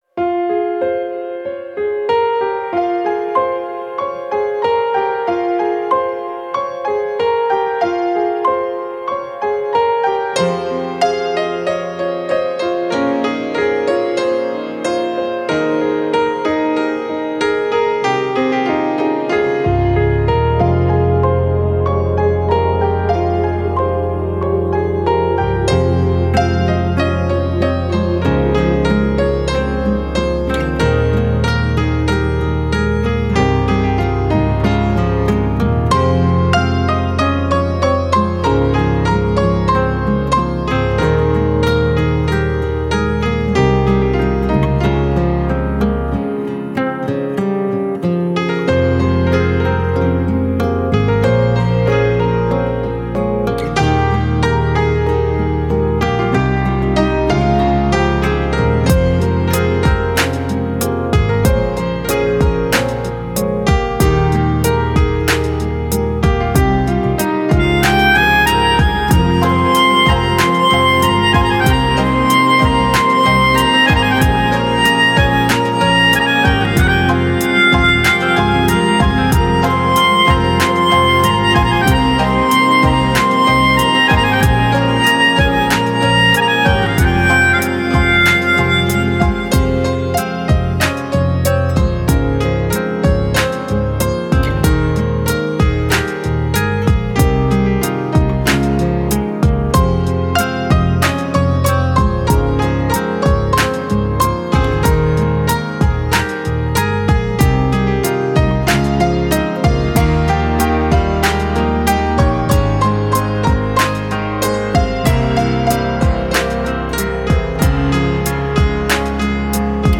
Instrumental Songs